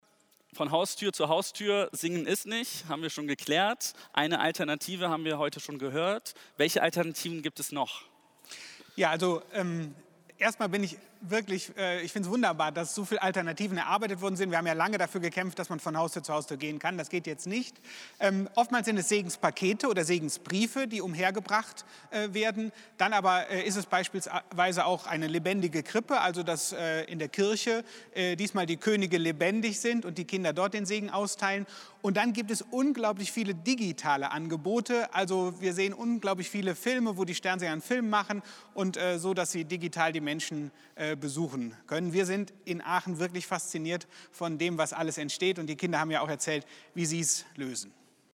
Mitschnitte aus dem Gottesdienst